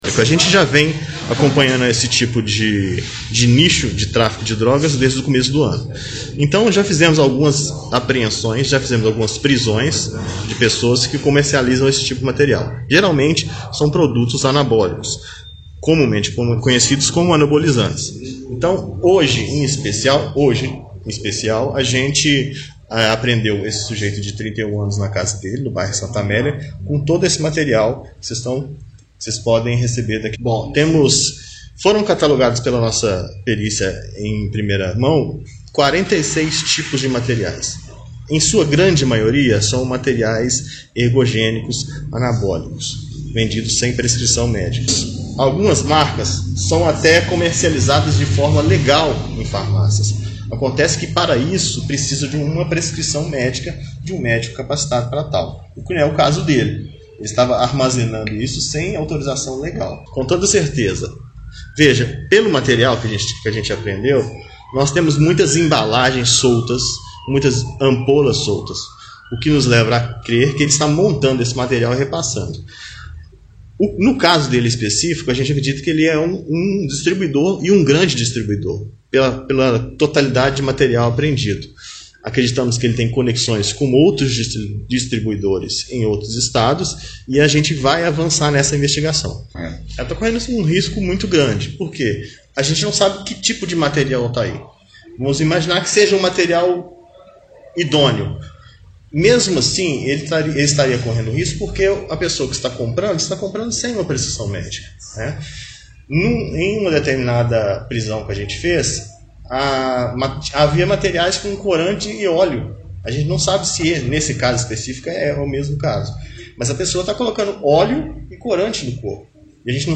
Coletiva-2.mp3